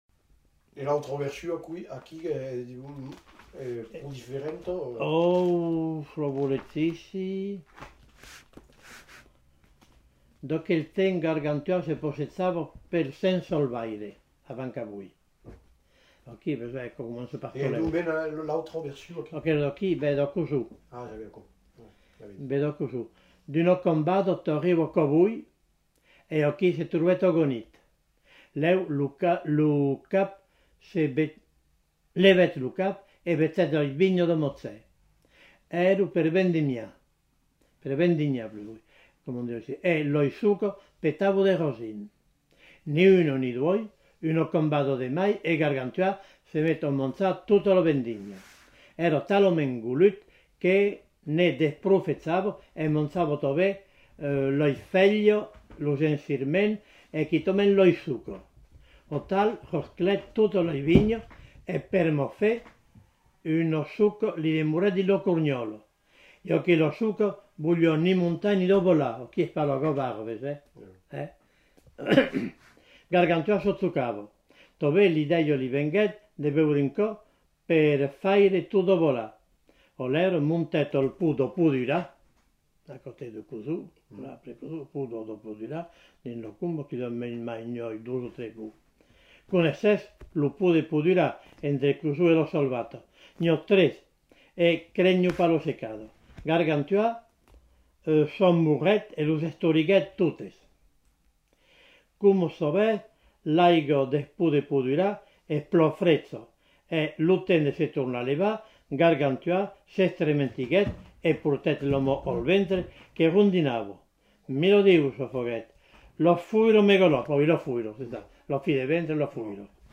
Lieu : Rocamadour
Genre : conte-légende-récit
Effectif : 1
Type de voix : voix d'homme
Production du son : lu
Classification : récit légendaire
Notes consultables : Un homme arrive en fin d'item.